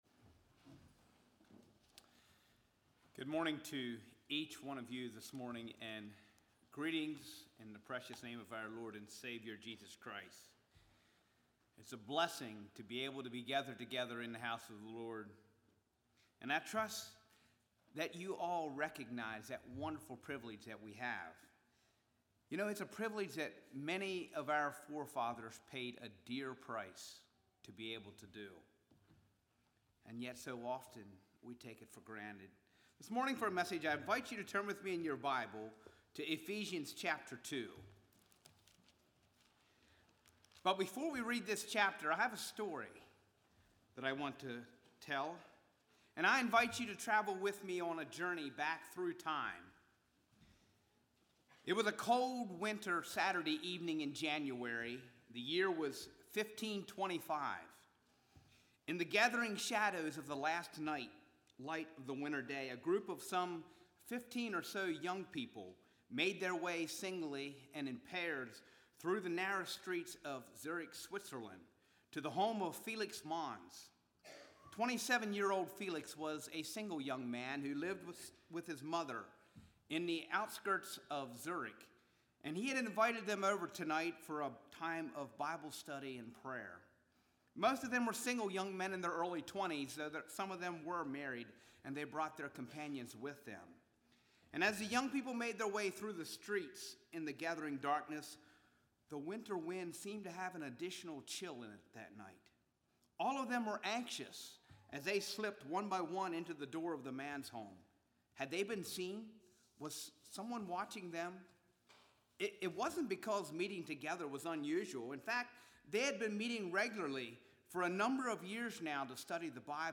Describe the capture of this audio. Congregation: Calvary